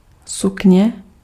Ääntäminen
UK : IPA : /skɜːt/ US : IPA : /skɜ˞t/